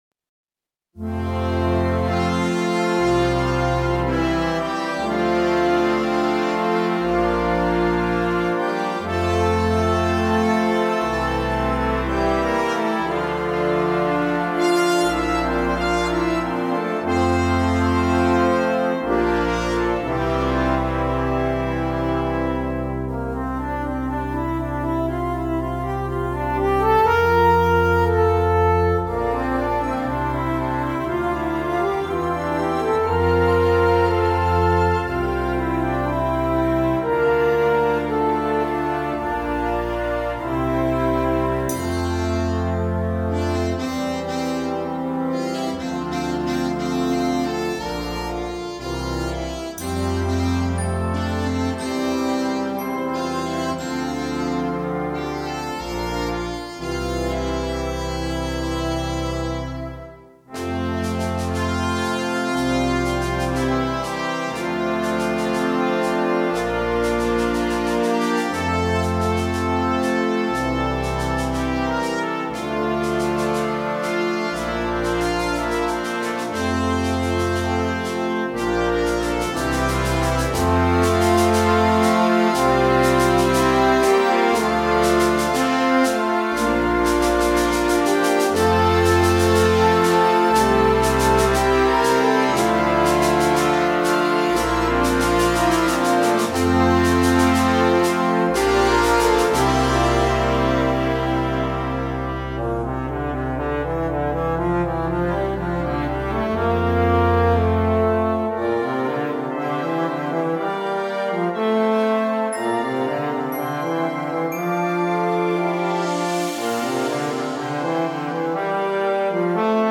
CategoryConcert Band
InstrumentationPiccolo
Flutes 1-2
Bb Clarinets 1-2-3
Eb Alto Saxophones 1-2
Bb Trumpets 1-2-3
Tenor Trombones 1-2
Euphonium
Tuba
Timpani
Glockenspiel
Bass Guitar